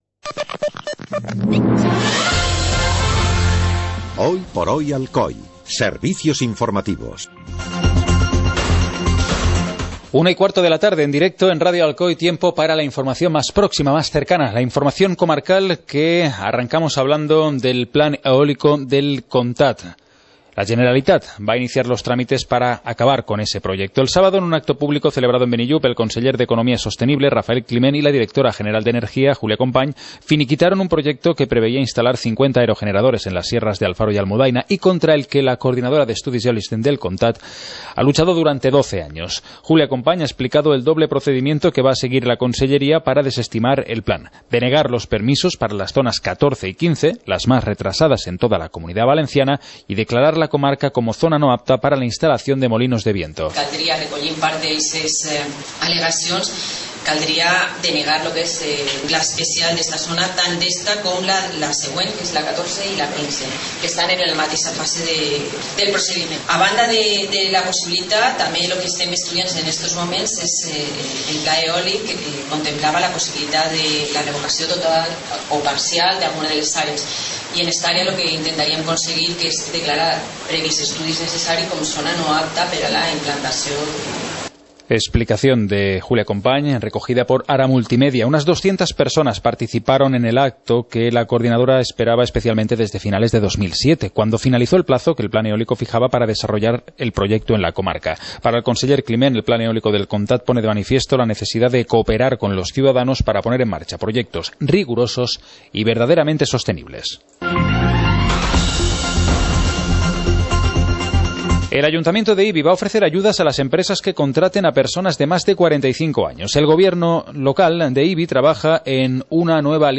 Informativo comarcal - lunes, 19 de octubre de 2015